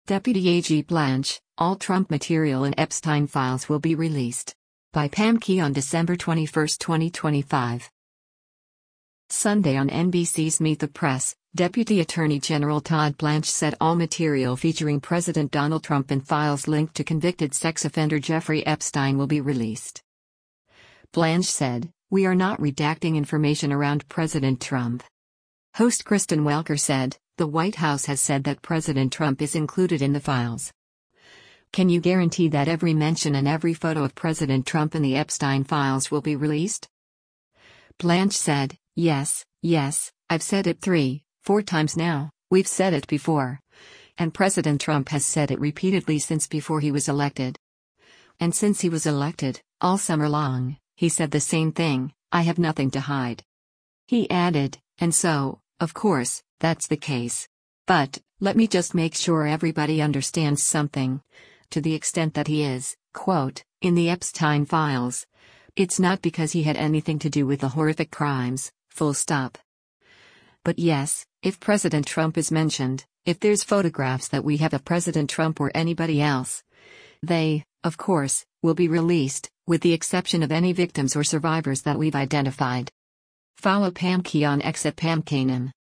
Sunday on NBC’s “Meet the Press,” Deputy Attorney General Todd Blanche said all material featuring President Donald Trump in files linked to convicted sex offender Jeffrey Epstein will be released.